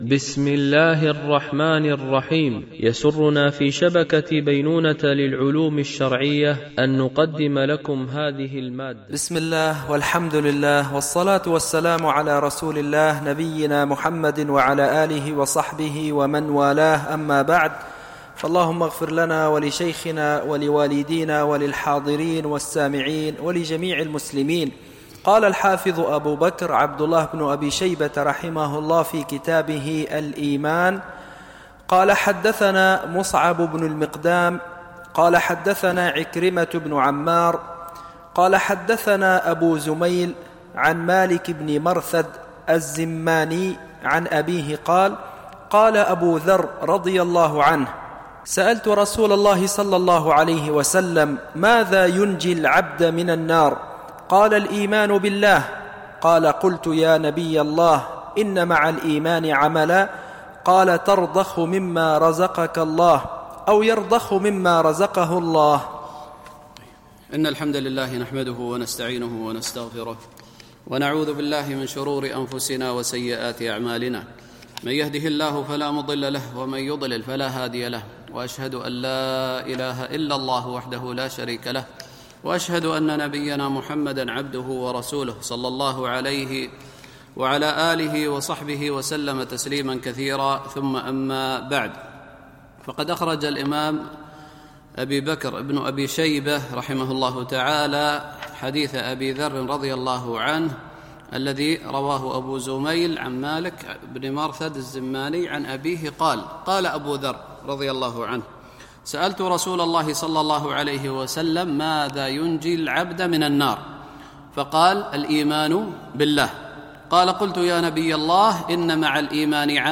شرح كتاب الإيمان لابن أبي شيبة ـ الدرس 22